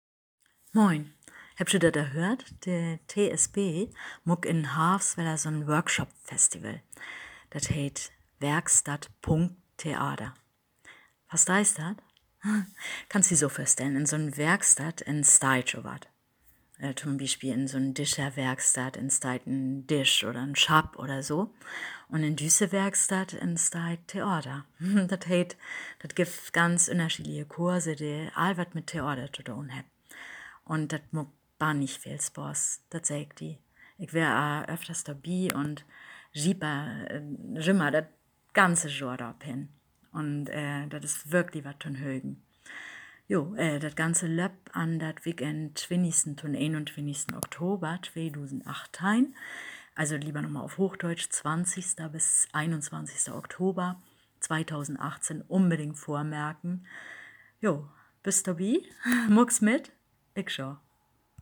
Schleswig-Holstein-Plattdütsch